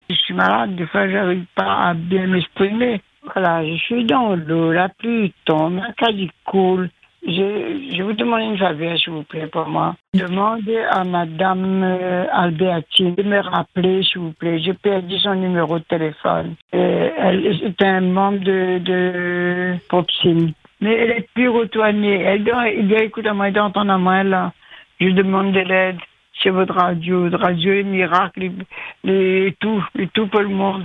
Un appel bouleversant reçu en direct sur Radio Free Dom.
À l’antenne, la voix tremblante mais déterminée, elle lance un véritable appel au secours, convaincue que Free Dom est, je cite, « une radio miracle ».